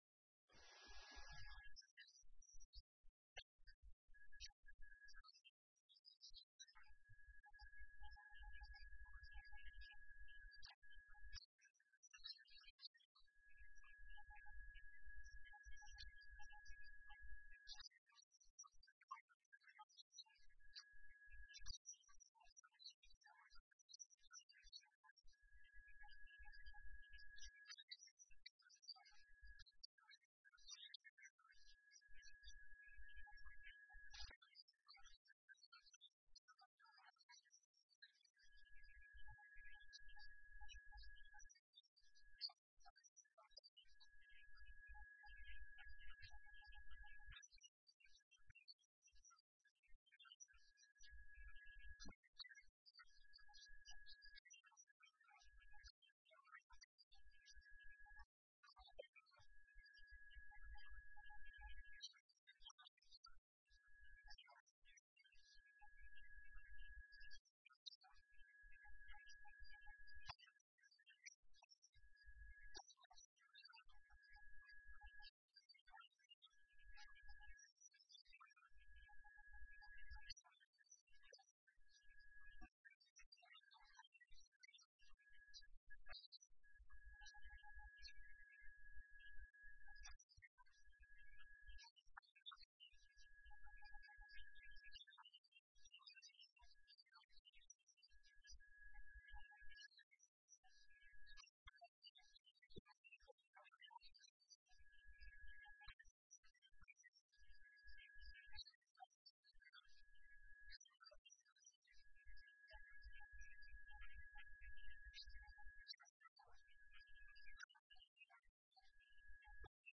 بیانات رهبر انقلاب در مراسم ششمین دوره‌ی فرماندهی و ستاد دانشگاه امام حسین(ع)